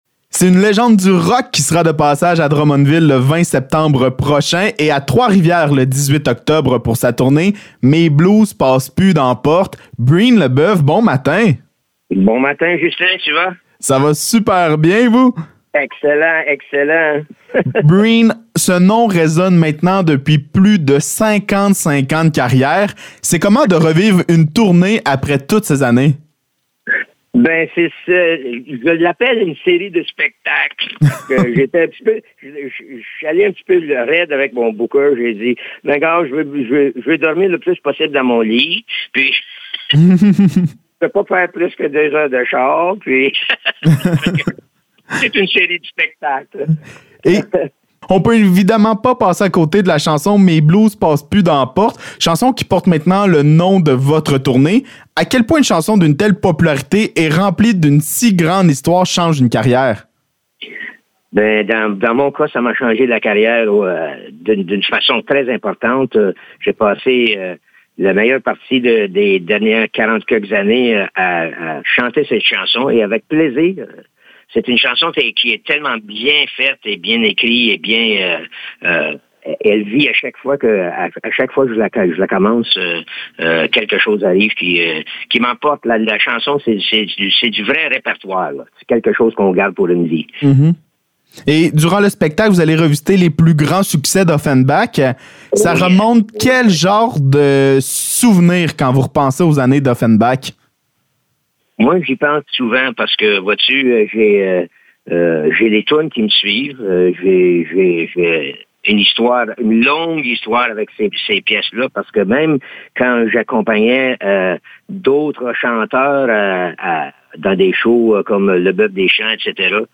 Entrevue avec Breen Leboeuf
ENTREVUE-BREEN-LEBOEUF-effets.mp3